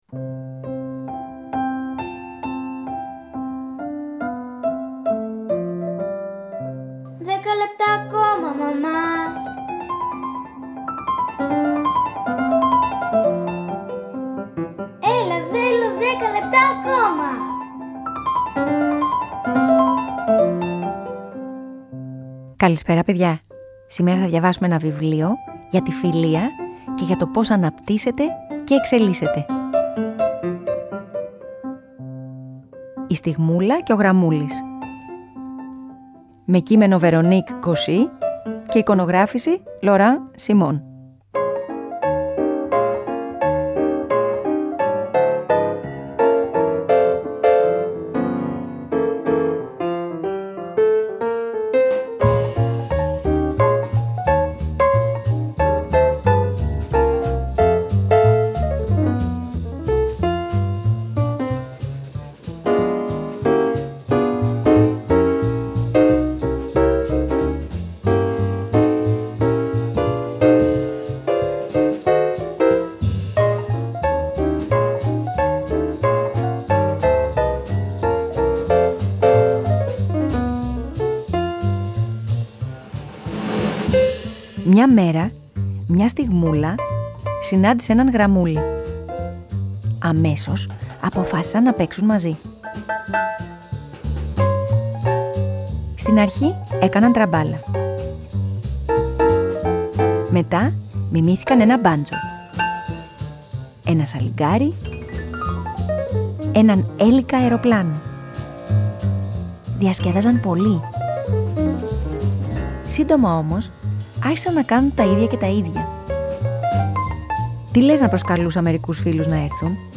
Σήμερα θα διαβάσουμε ένα βιβλίο για τη φιλία. Και όταν η φιλία είναι σωστή τότε αναπτύσσεται, μεγαλώνει και δυναμώνει κι η χαρά.